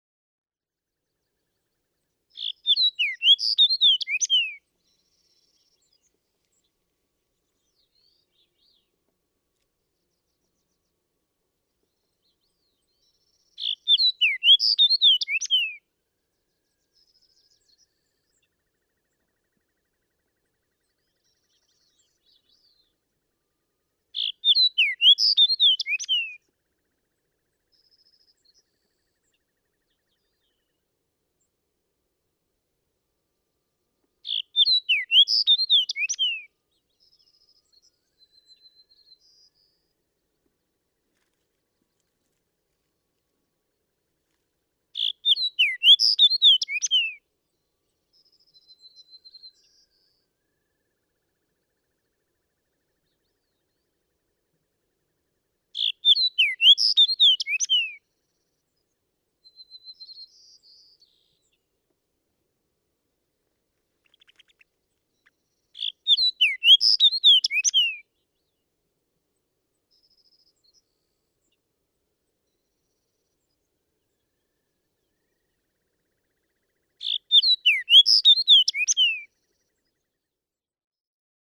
Fox sparrow
286_Fox_Sparrow.mp3